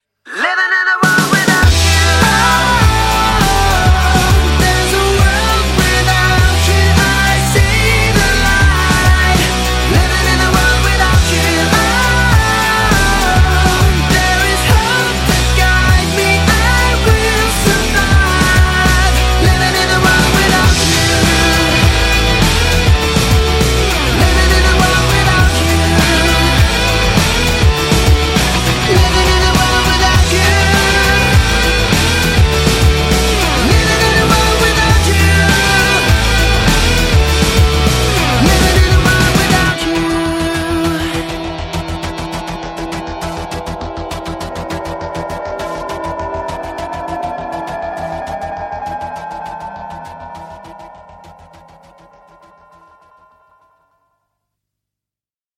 • Качество: 192, Stereo
мужской вокал
громкие
Alternative Rock